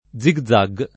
zigzag [ +z i gz#g ] o zig zag [ id. ] s. m. — es.: andando così da destra a sinistra, e, come si dice, a zig zag [ and # ndo ko S& dda dd $S tra a SS in &S tra, e, k 1 me SS i d &© e, a zz i g z#g ] (Manzoni)